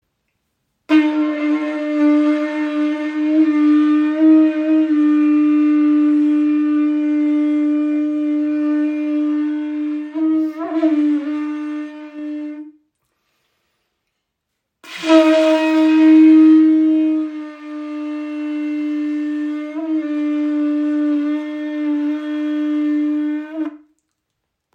• Poliert: Sanftes Mundstück für komfortables Spielen bei kraftvollem, tragendem Klang.
Kuhhorn - Signalhorn poliert Nr. 3